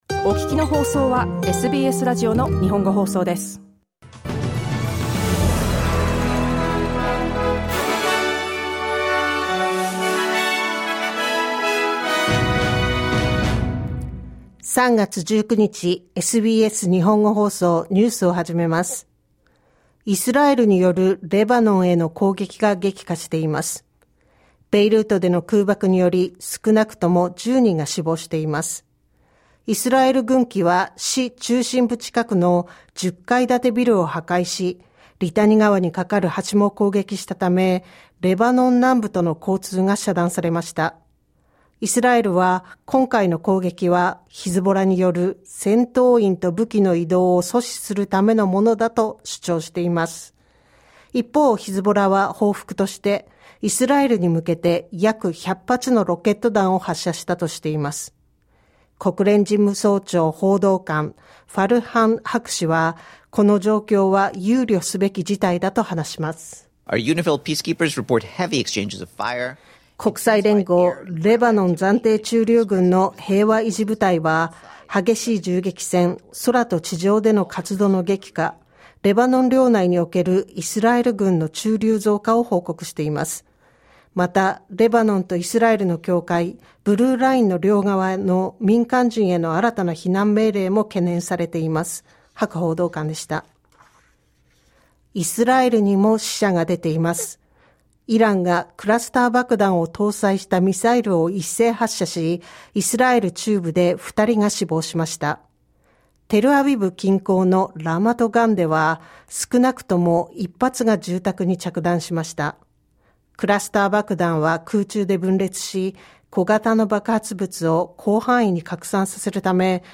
SBS Japanese News for Thursday 19 March
News from today's live program (1-2pm).